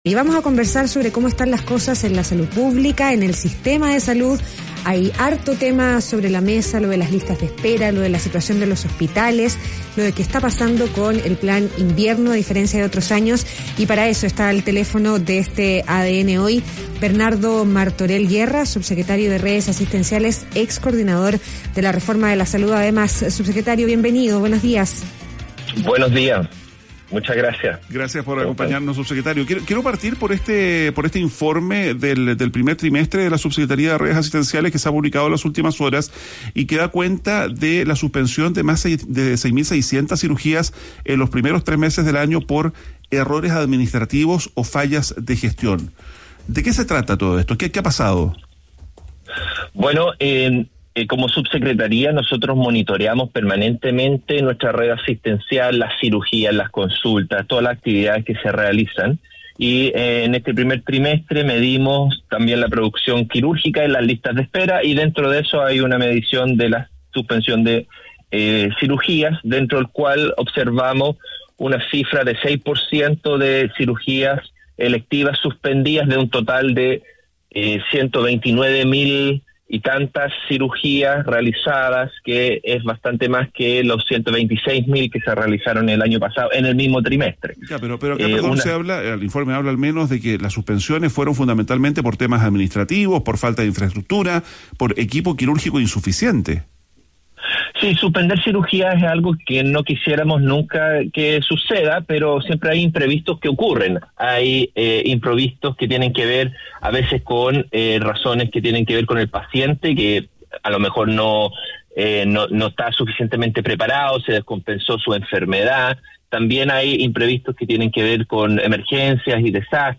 Bernardo Martorell Guerra, subsecretario de Redes Asistenciales, conversa con ADN Hoy